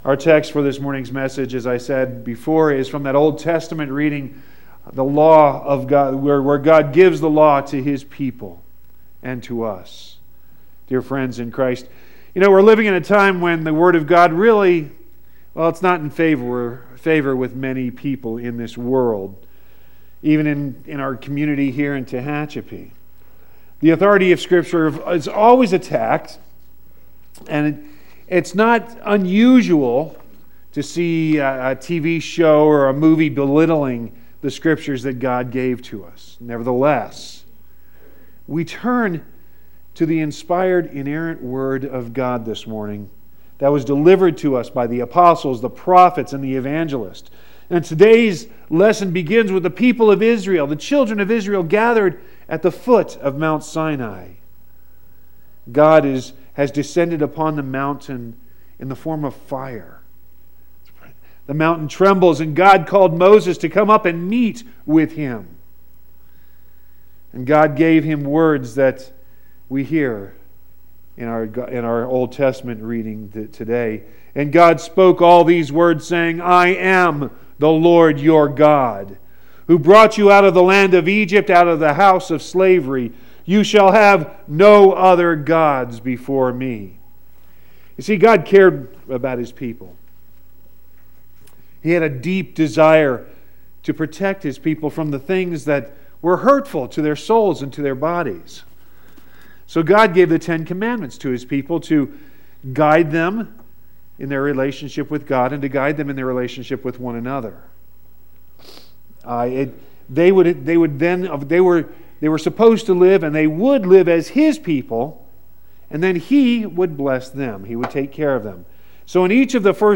3-4-18-sermon.mp3